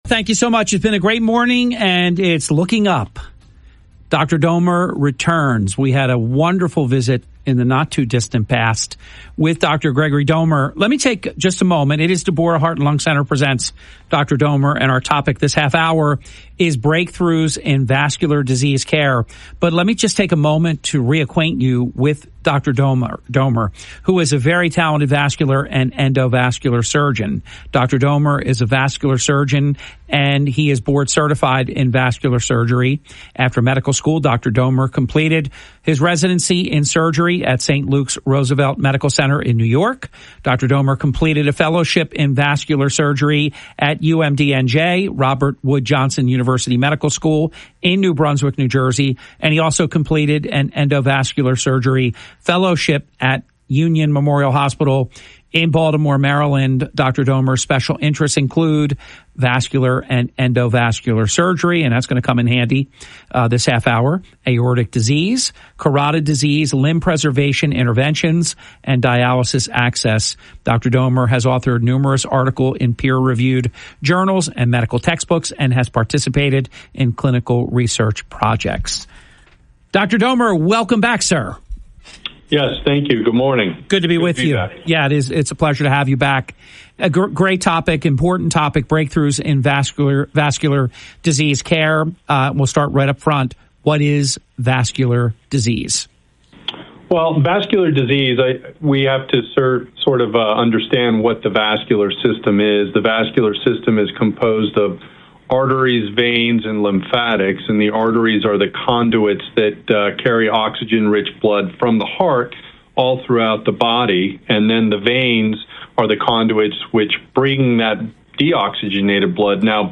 On a recent episode of the Hurley in the Morning radio show